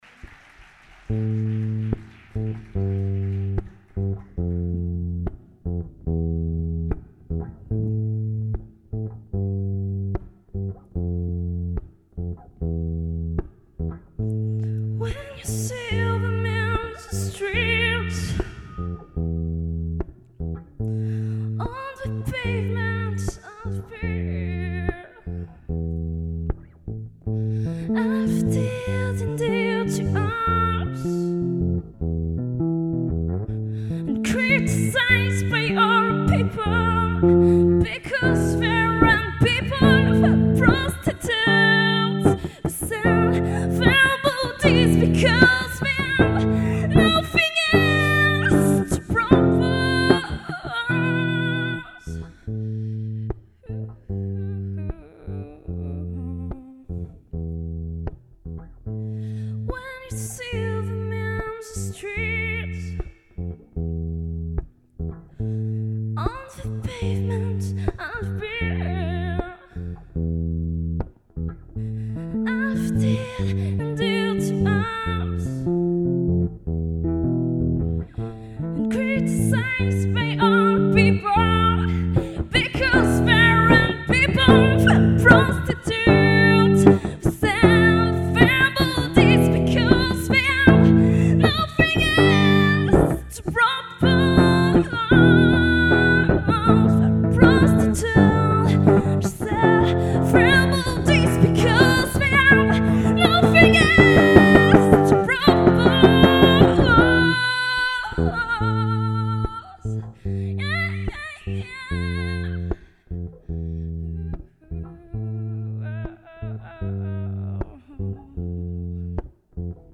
Une composition de mon ex groupe sinon...
EDIT : je précise que je n'avais qu'un an de basse lorsque j'ai fait ce concert là...
sympa comme tout !!  si elle est aussi jolie qu'elle chante bien !!!!   wouaaaaa chica boomm
13h24 - When You See Them In The Street (Live).mp3